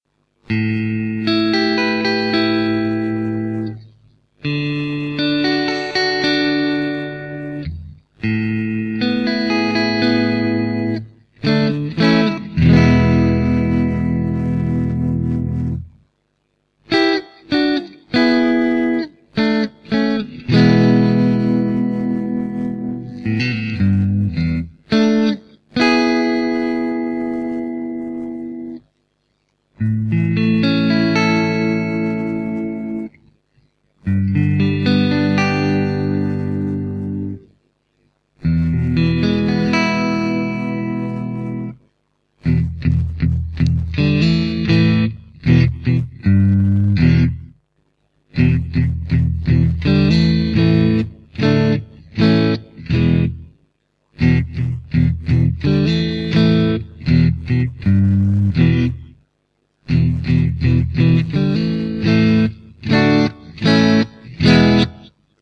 Chimera alnico 5 stratocaster pickup, hot texas strat tones, the hendrixy one
The Chimera is a classic overwound Strat pickup. More warmth and drive than a Nymph but still wholly vintage in character.
There's still plenty of quack from the in between positions and the tight, snappy bridge provides all the bite you could want, as well as extra power.
Listen here:     Bridge    Bridge & Middle     Middle